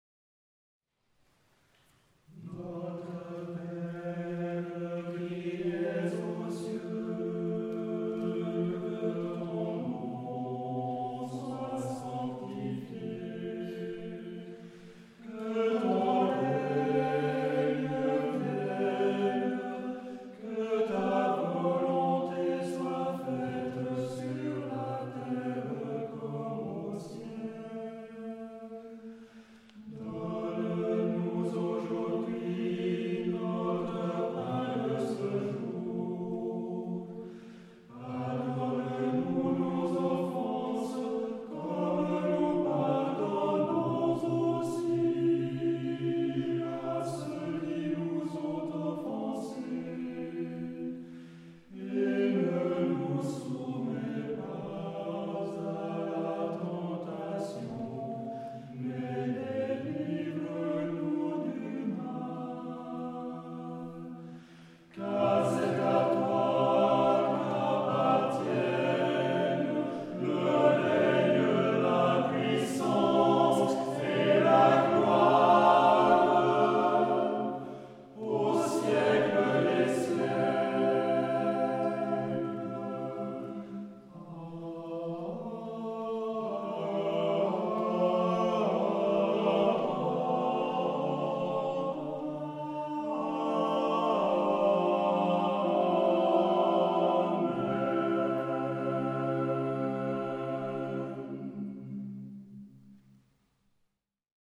- le vendredi 30 septembre 2016 à 20h00, à l'église romane de Saint-Sulpice,
Notre Père, de Dominique Gesseney-Rappoz, avec l'aimable autorisation du compositeur et de l'éditeur Les éditions Labatiaz, sur le site desquelles vous trouverez les partitions originales ; enregistré à St-Sulpice